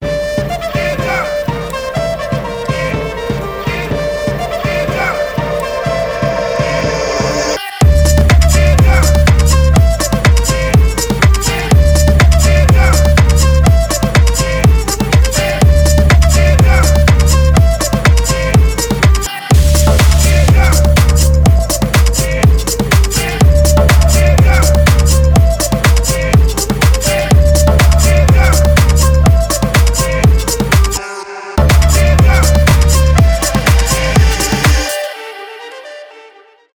этнические , house
флейта , танцевальные
electronic